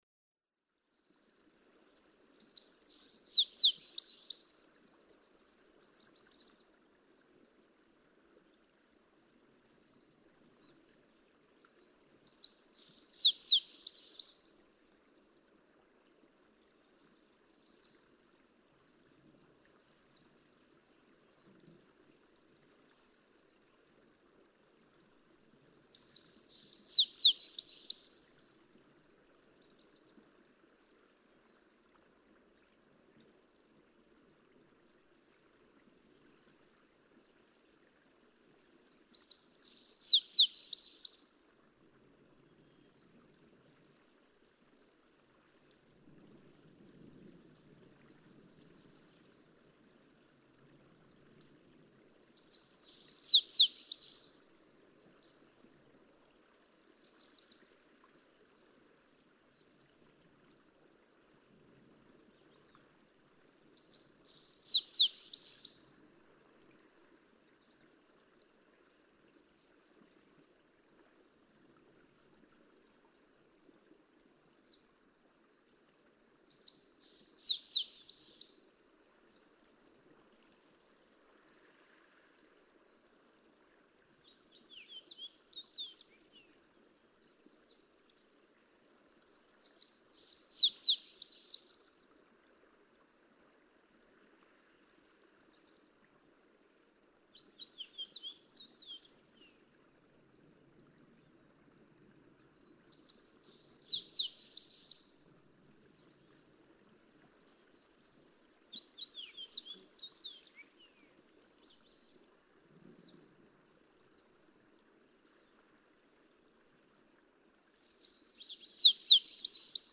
American tree sparrow
♫272, ♫273, ♫274—longer recordings from those three individuals
272_American_Tree_Sparrow.mp3